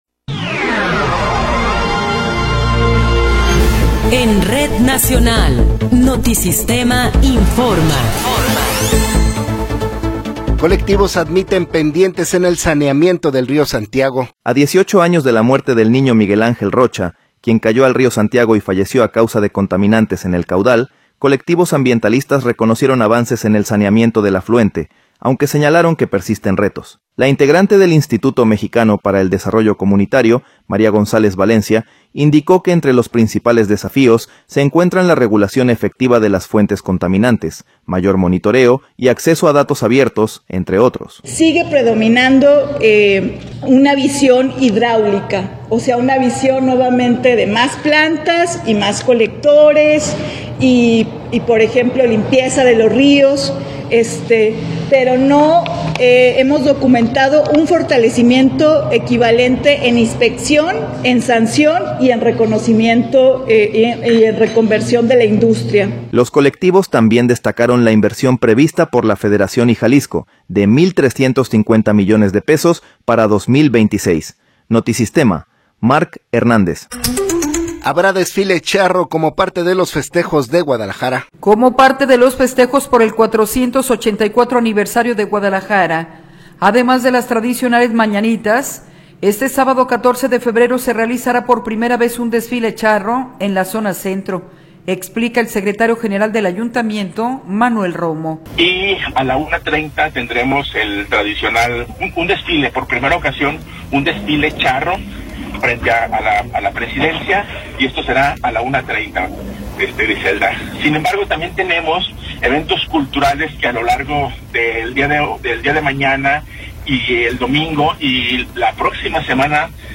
Noticiero 13 hrs. – 13 de Febrero de 2026
Resumen informativo Notisistema, la mejor y más completa información cada hora en la hora.